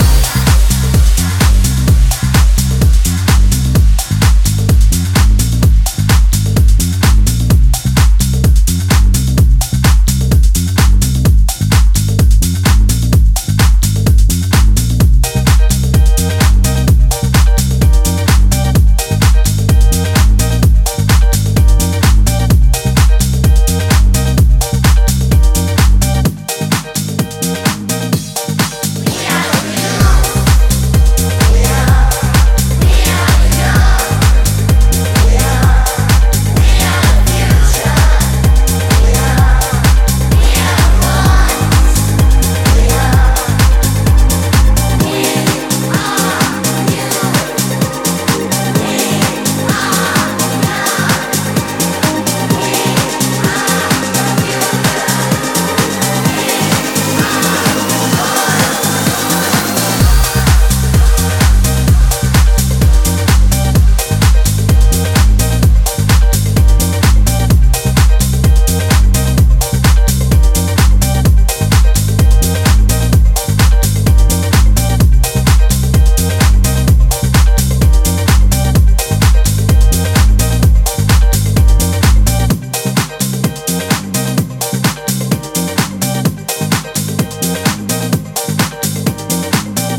もう少しアップリフトに仕立てたそちらもやはりさじ加減が絶妙です！